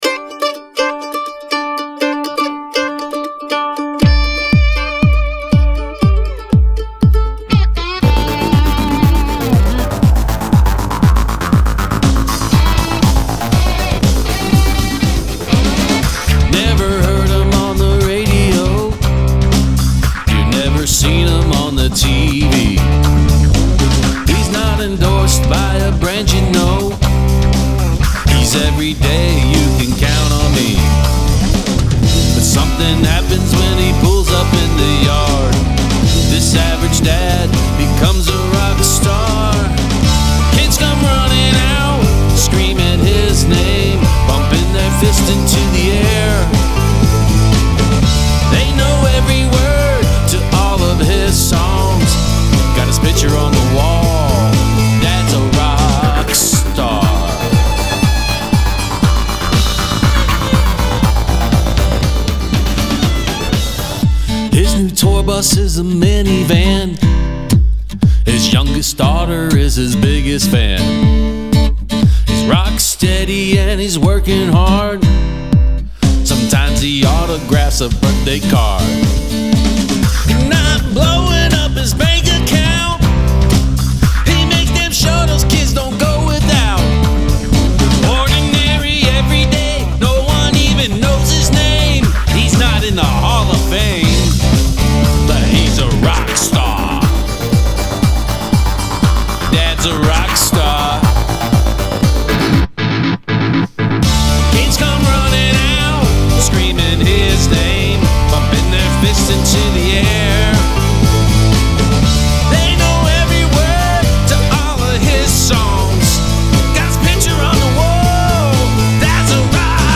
A little country with electronica.